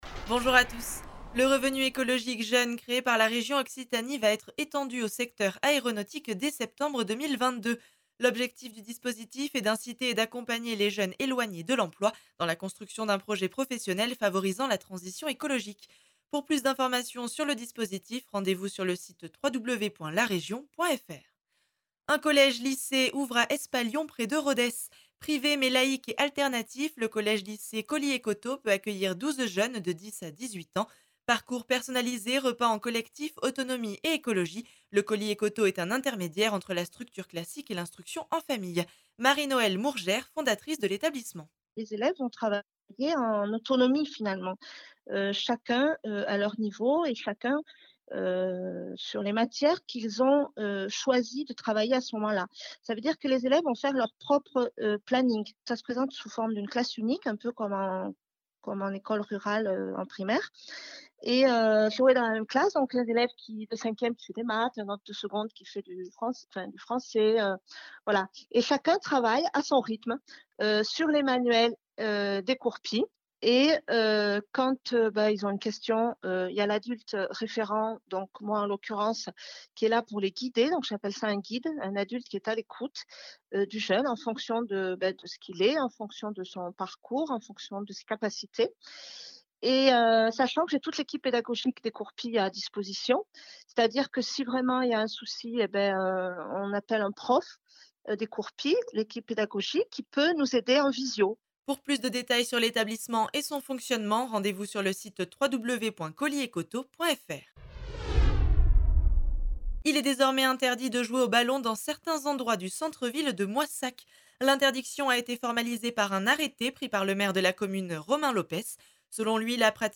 L’essentiel de l’actualité de la région Occitanie en 3 minutes. Une actualité centrée plus particulièrement sur les départements de l’Aveyron, du Lot, du Tarn et du Tarn & Garonne illustrée par les interviews de nos différents services radiophoniques sur le territoire.